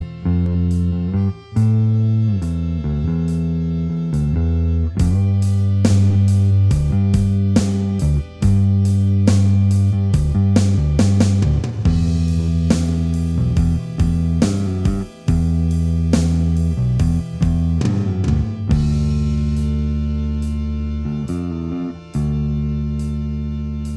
Minus Acoustic Guitar Indie / Alternative 4:34 Buy £1.50